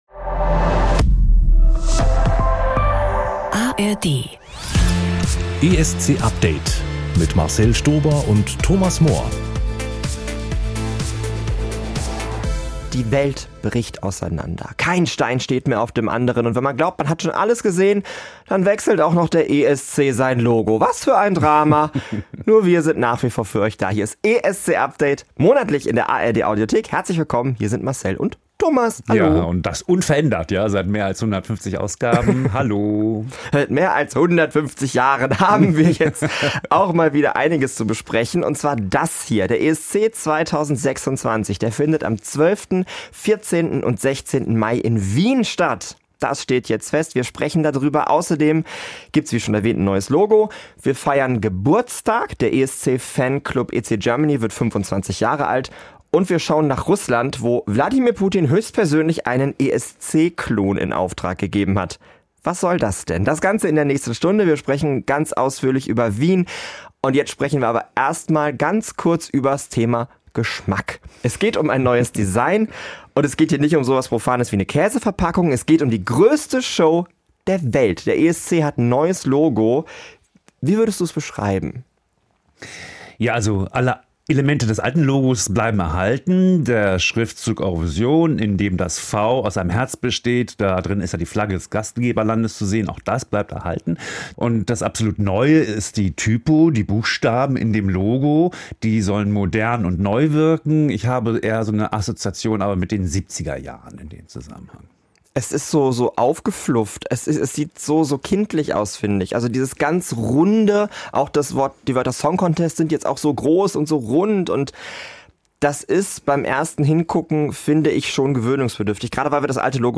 Einmal im Monat gibt es aktuelle Hintergrundinformationen und viel Musik aus der schillernden Welt des ESC.